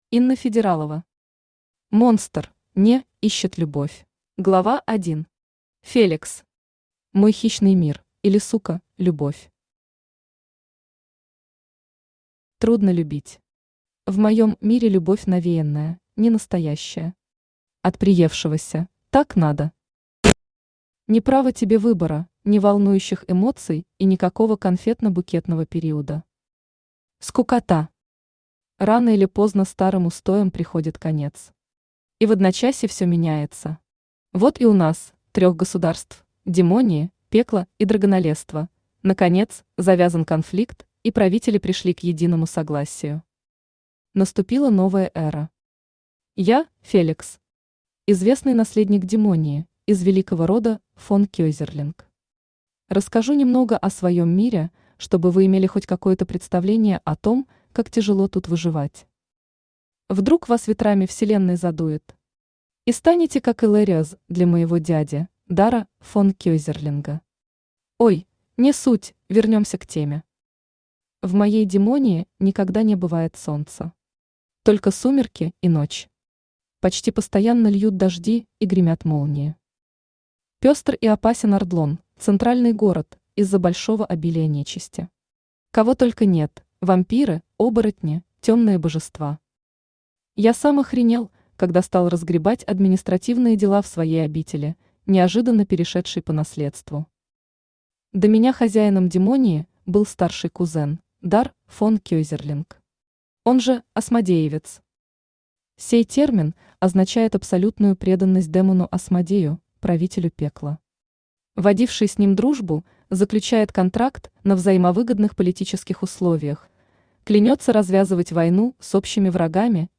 Aудиокнига Монстр (не)ищет любовь Автор Инна Федералова Читает аудиокнигу Авточтец ЛитРес. Прослушать и бесплатно скачать фрагмент аудиокниги